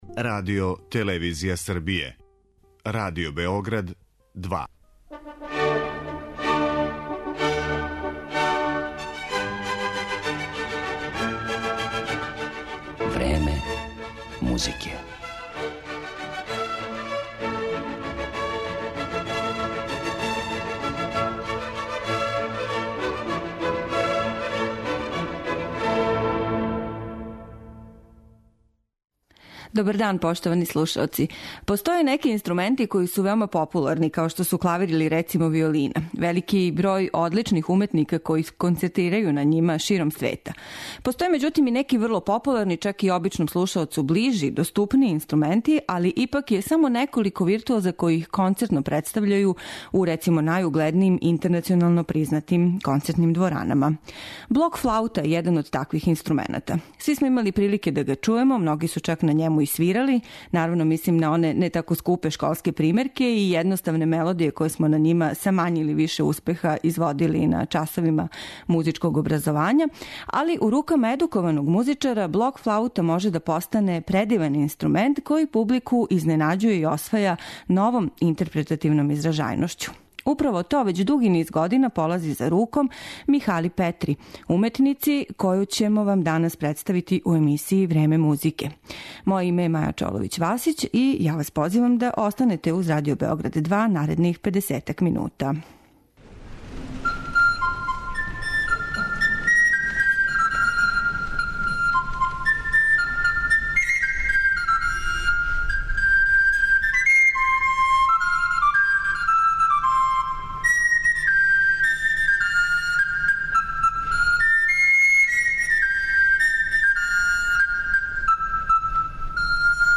блок флаути